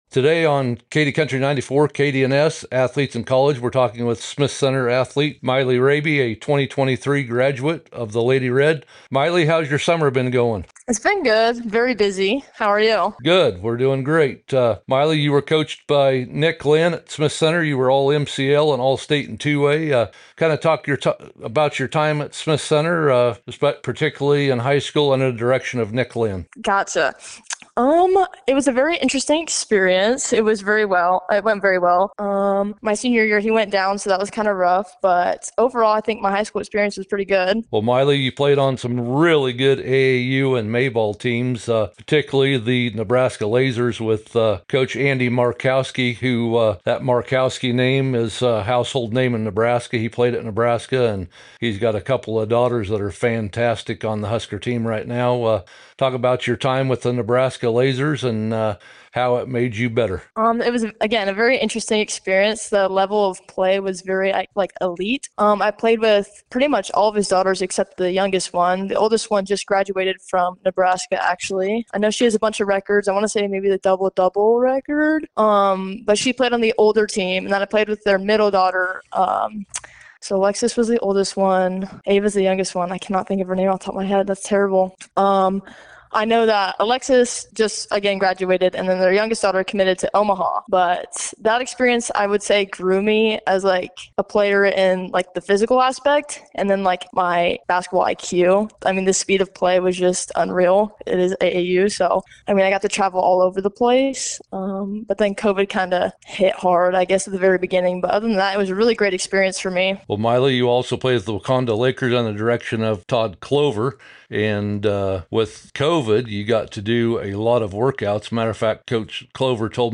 KDNS SPORTS PODCAST